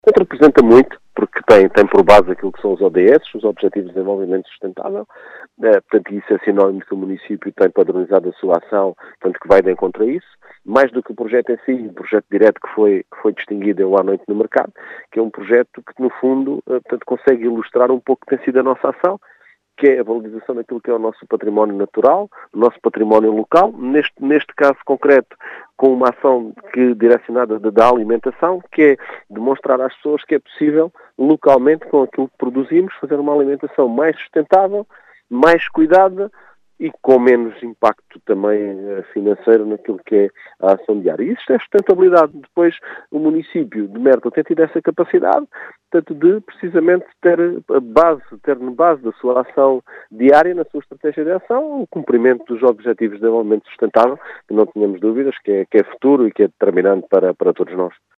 Em declarações à Rádio Vidigueira, Mário Tomé, presidente da Câmara Municipal de Mértola, realça a importância da “valorização do património natural local”.